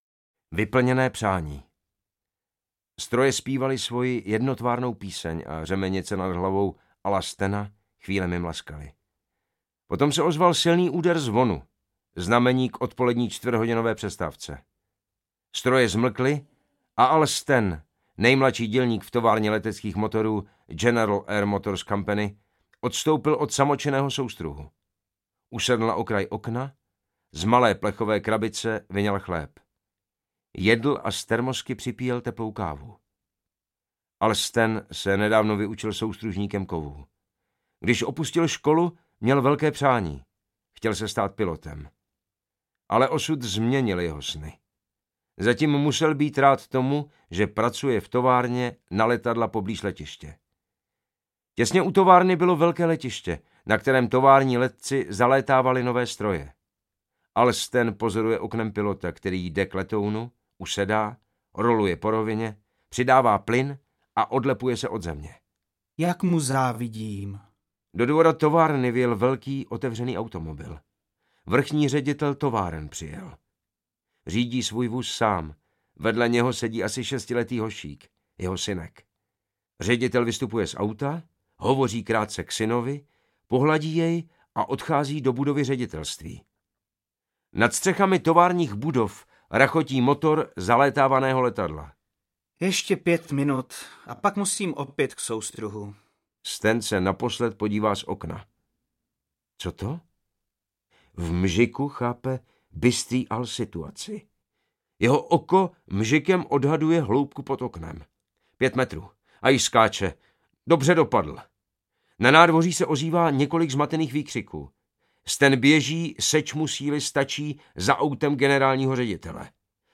Audio kniha
Ukázka z knihy
Kvartetu vypravěčů vévodí Ondřej Vetchý, který svým rozechvělým, znepokojivě a dramatickým projevem umí napětí násobit.